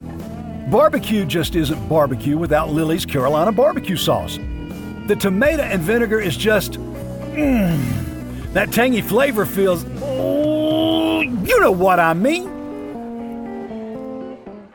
Commercial - Lillie's BBQ Sauce! - Food & Beverage
English - USA and Canada
Middle Aged
Home Studio (AT2020, ProSonus AudioBox, MacBook Pro, Audacity)